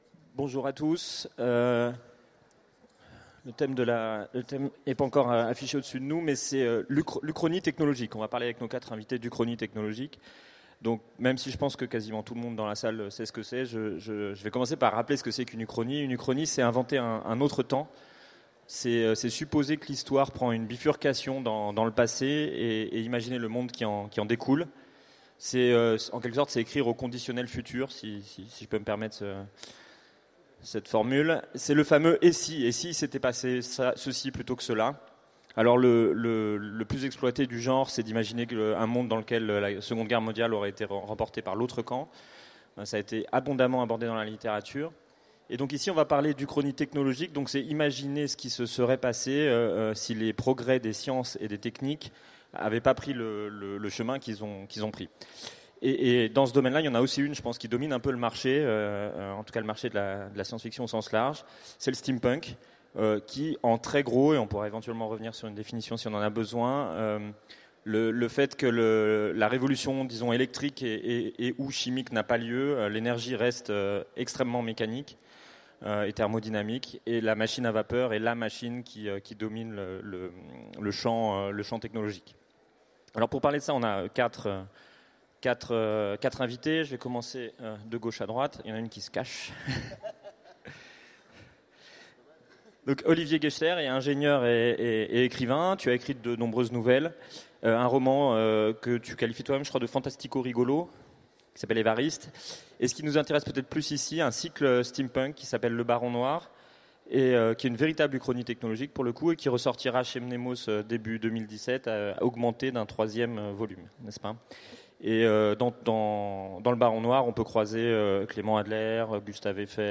Utopiales 2016 : Conférence L’uchronie technologique